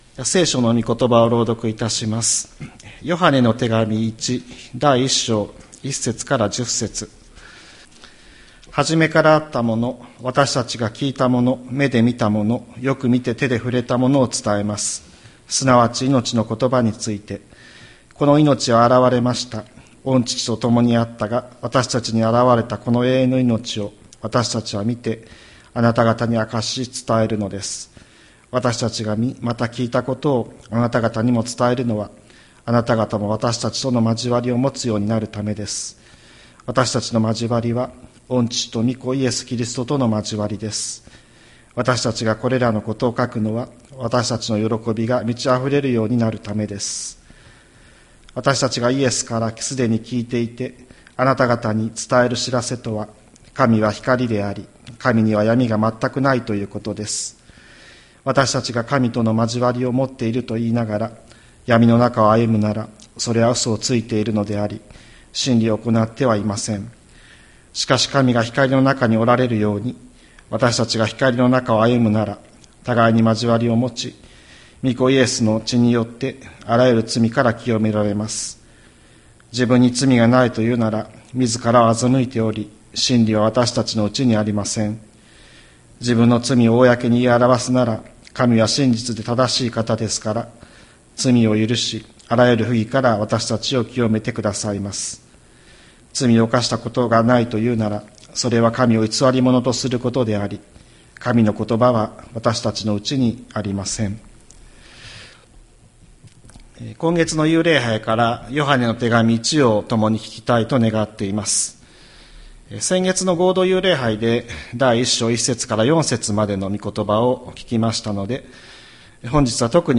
2025年04月27日朝の礼拝「光の中を歩もう」吹田市千里山のキリスト教会
千里山教会 2025年04月27日の礼拝メッセージ。